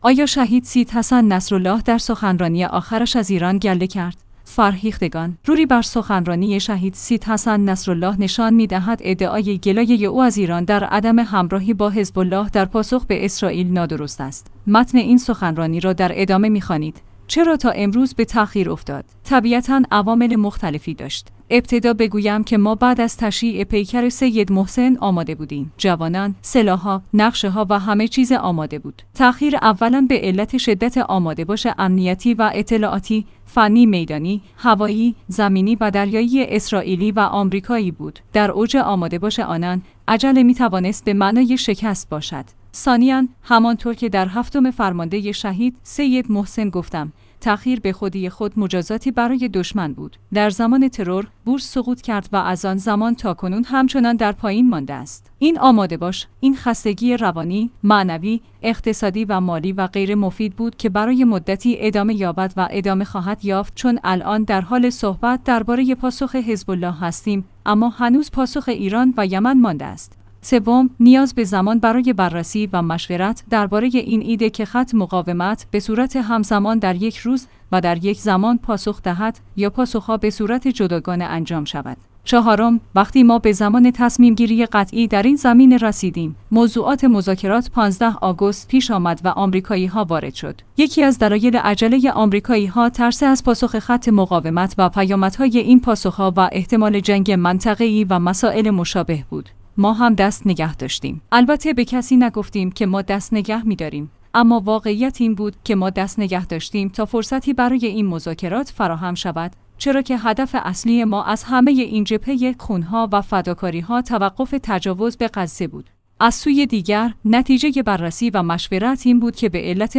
آیا شهید سیدحسن نصرالله در سخنرانی آخرش از ایران گله کرد؟